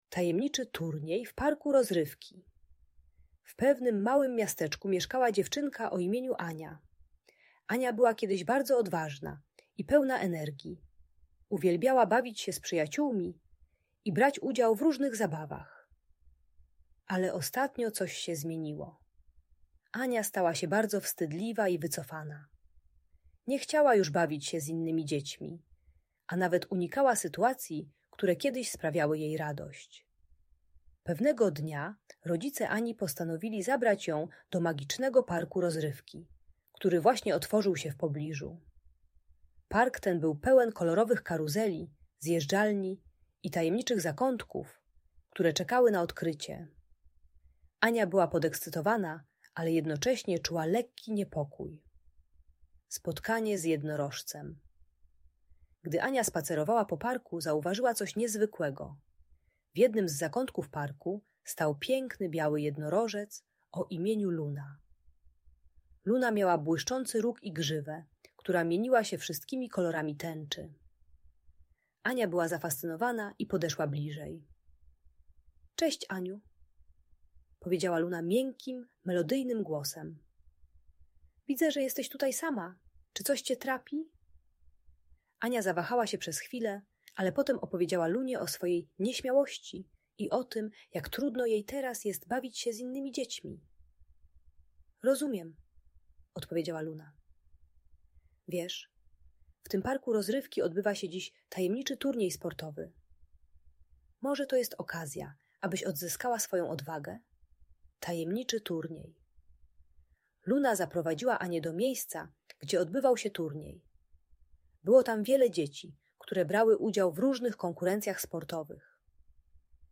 Tajemniczy Turniej: Historia o Odwadze i Przyjaźni - Audiobajka